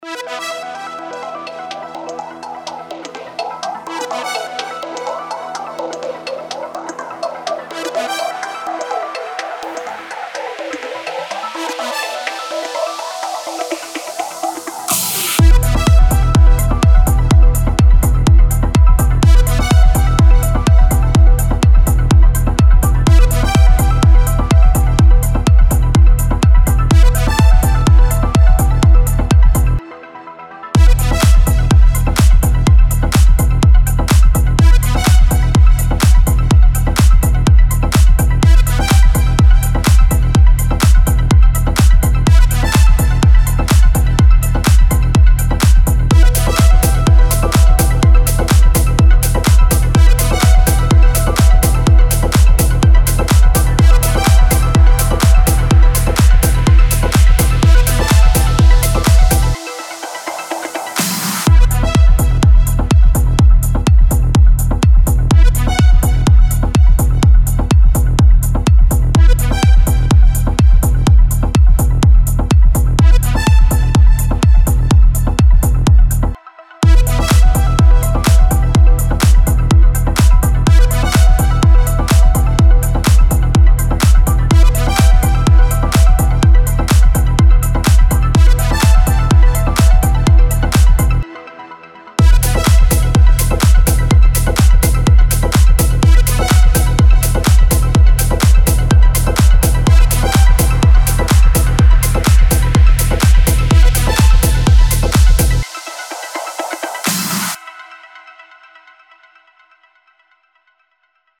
Звуки примерные, чтобы не отбивались от настроения.